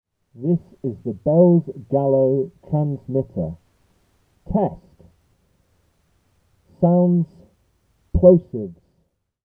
We connected the output from the transformer to a balanced microphone input on a UA Volt 2 interface, and spoke loudly into the diapghragm.
Higher supply voltage.
The sound is somewhat muffled and very peaky, but the voices are just about intelligible.